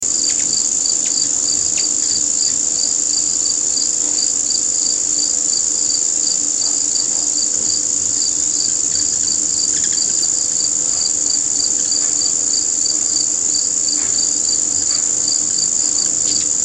AMBIANCES SONORES DE LA NATURE
Mexique - été